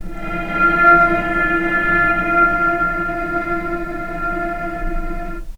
healing-soundscapes/Sound Banks/HSS_OP_Pack/Strings/cello/sul-ponticello/vc_sp-F4-pp.AIF at b3491bb4d8ce6d21e289ff40adc3c6f654cc89a0
vc_sp-F4-pp.AIF